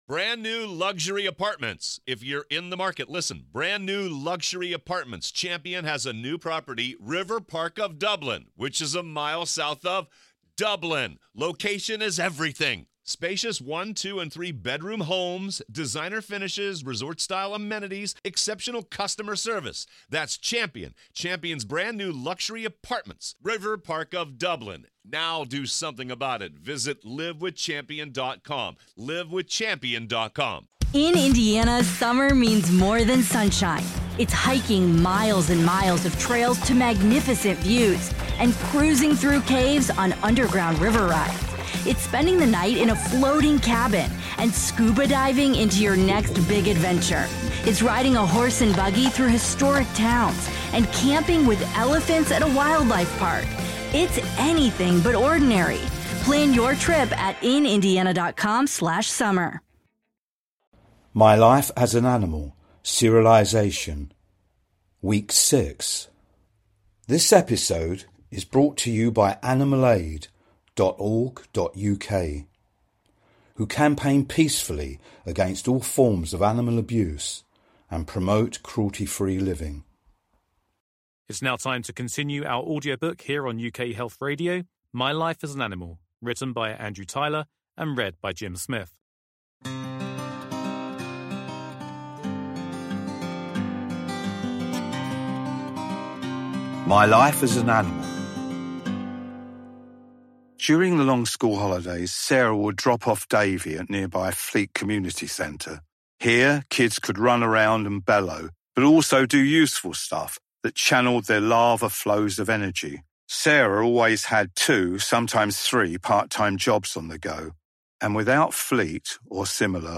UK Health Radio is running its first ever Book serialisation!
It is beautifully written and sensitively voiced.